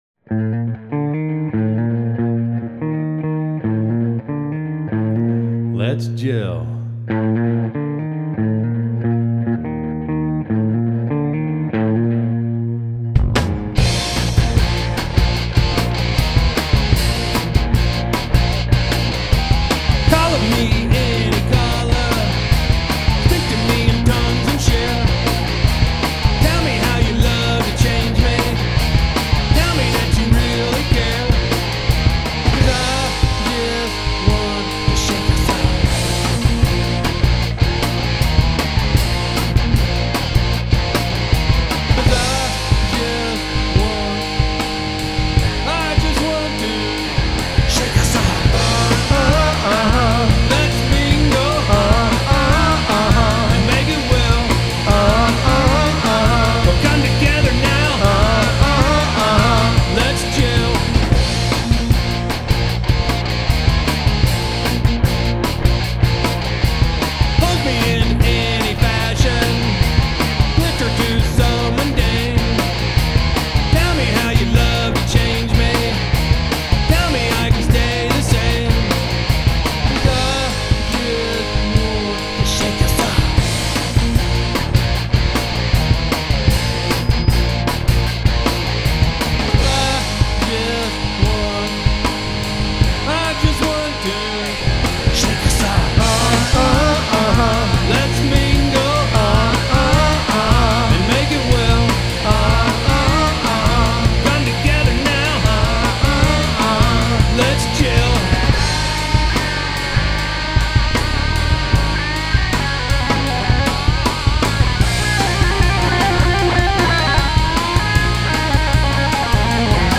Bass and Vocals
Rythm Guitar